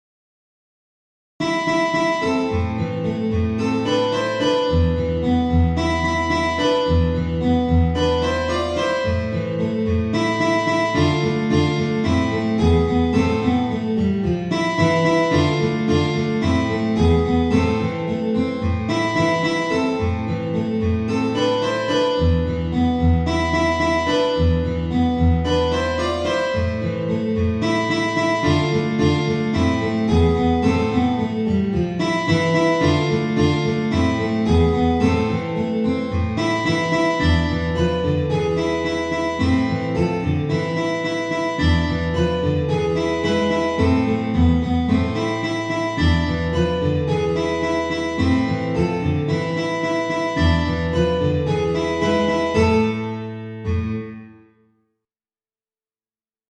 Für 3 Gitarren
Ensemblemusik
Trio
Gitarre (3)